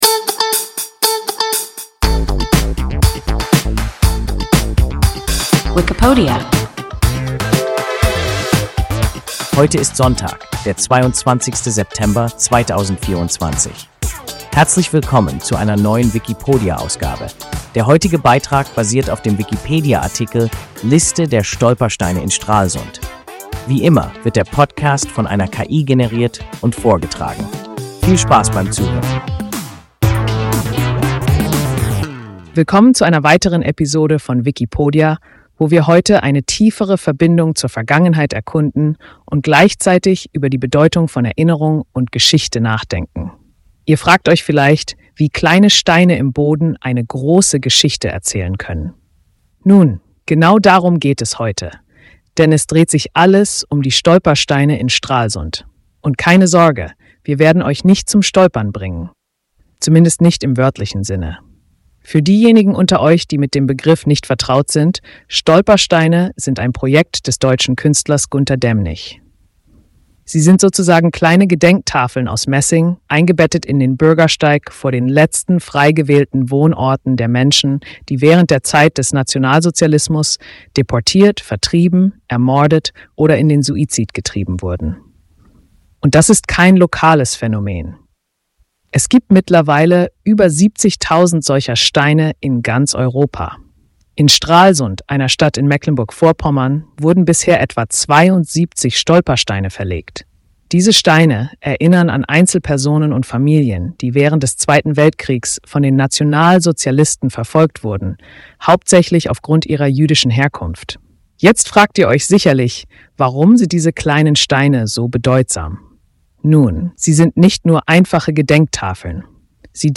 Wikipodia – ein KI Podcast